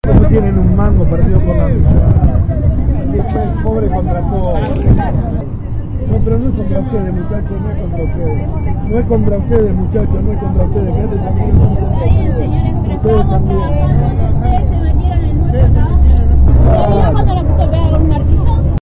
Todo sucedió esta mañana en Iriarte y la bajada de la Autopista Buenos Aires-La Plata, en las inmediaciones de la Ribera de Quilmes.